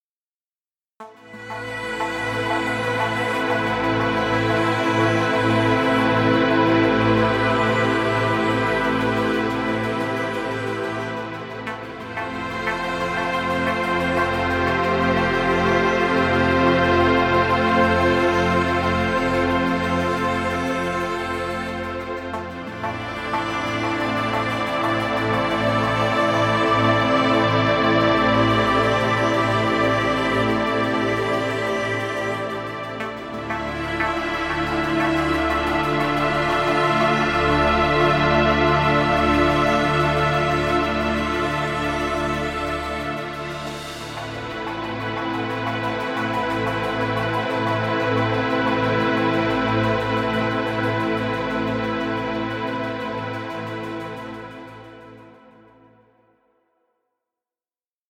Meditative music for video.